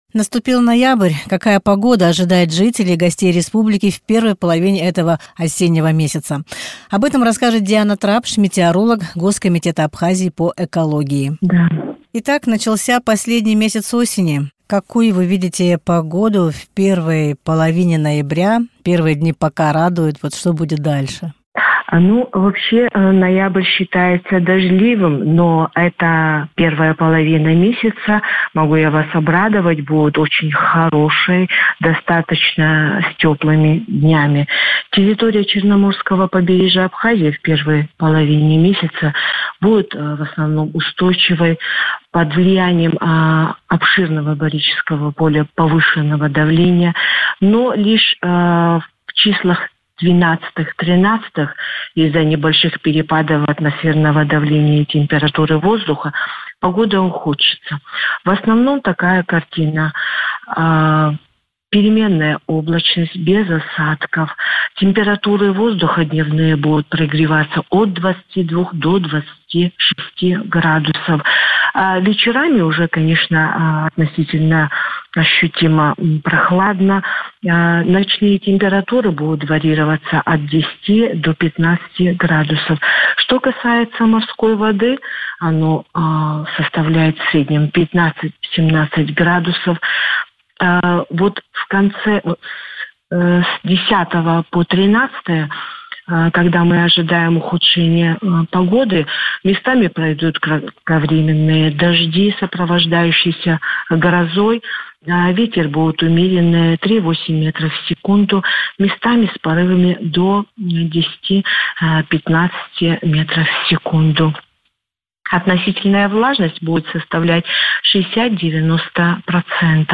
метеоролог